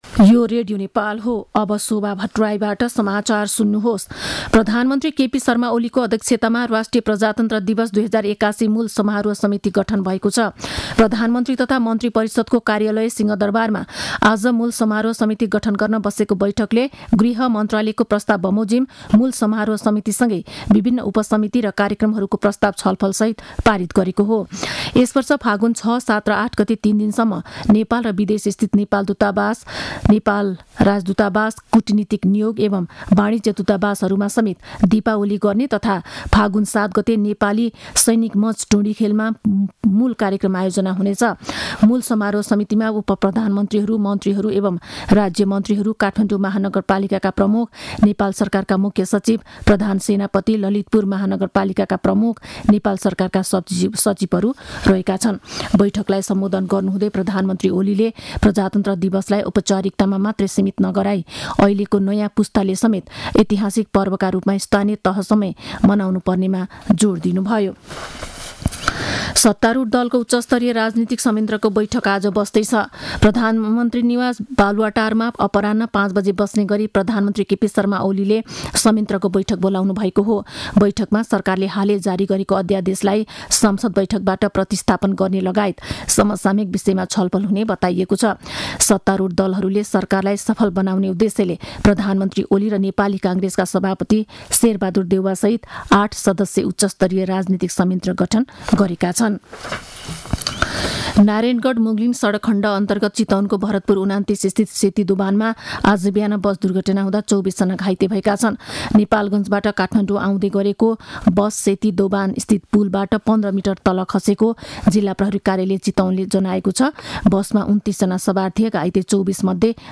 दिउँसो १ बजेको नेपाली समाचार : २८ माघ , २०८१
1-pm-news-1-3.mp3